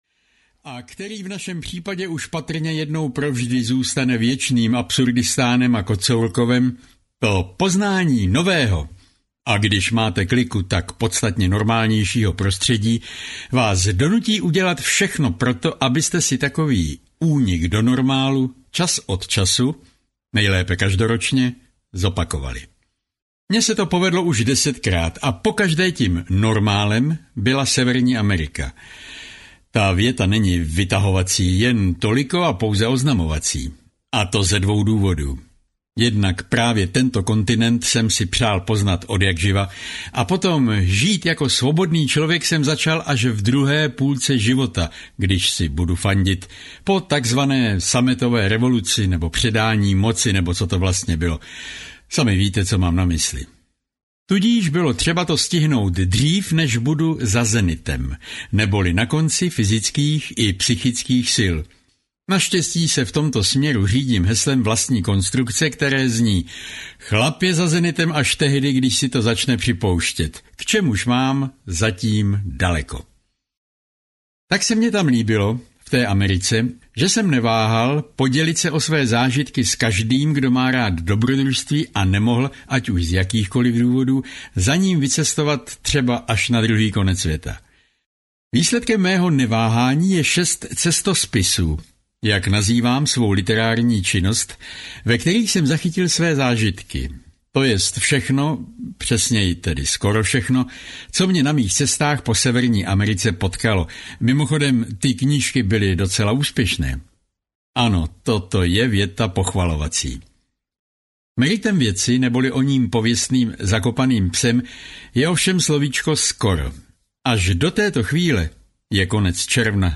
Poslední zlato z Yukonu audiokniha
Ukázka z knihy